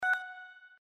voicebeep.mp3.svn-base